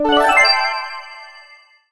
get_pickup_06.wav